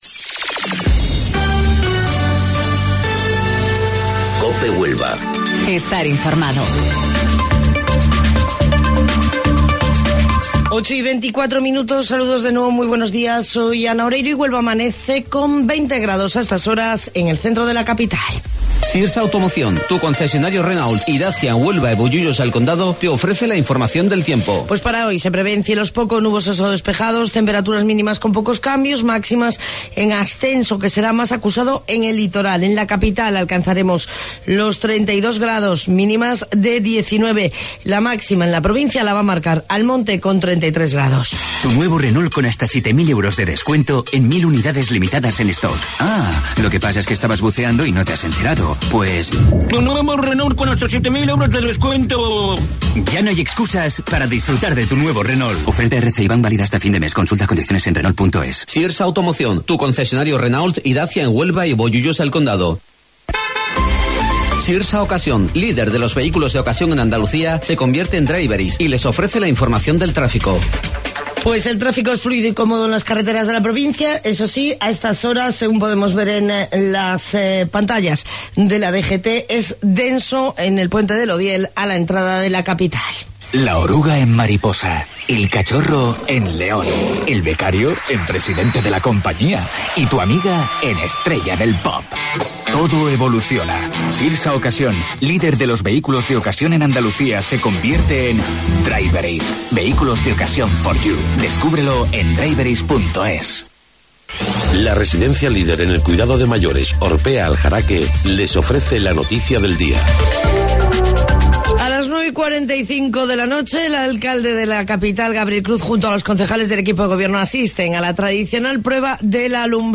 AUDIO: Informativo Local 08:25 del 29 de Julio